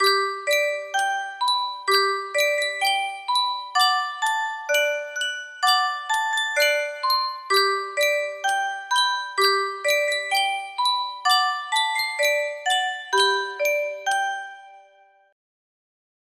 Sankyo Music Box - Skip to My Lou GC music box melody
Full range 60